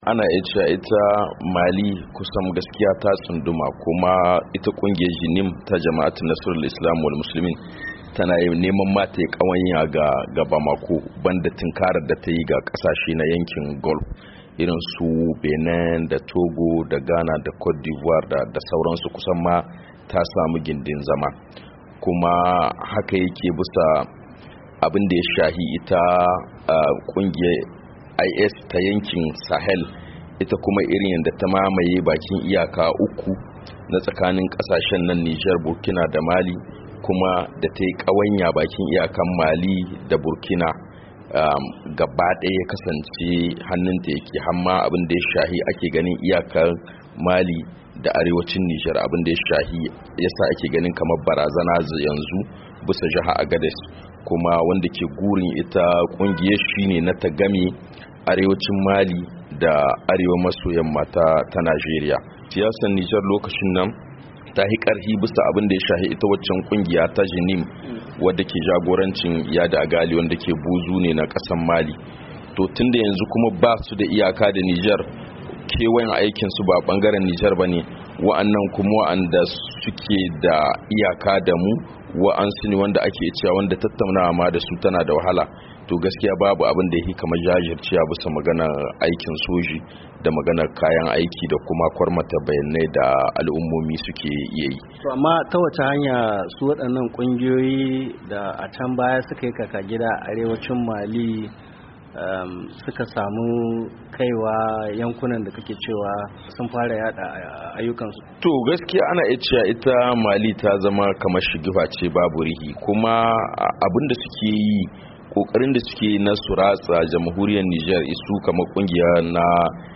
Saurari cikakkiyar hirar su da wakilin Muryar Amurka